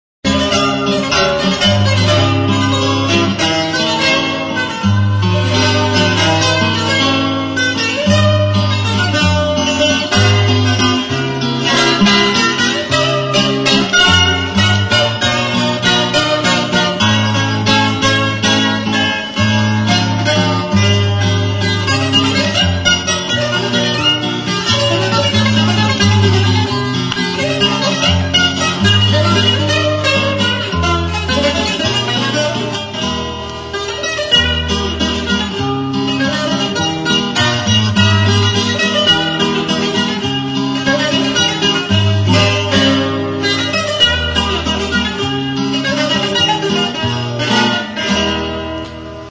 Stringed -> Plucked
Sei soka bikoitzeko kordofonoa da.